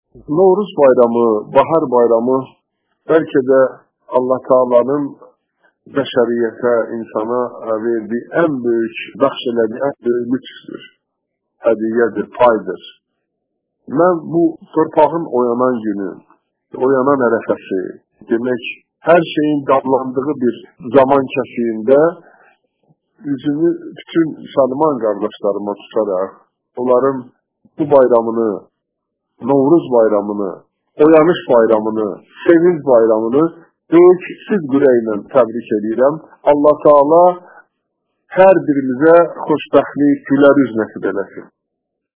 Novruz bayramı + reportaj (Audio)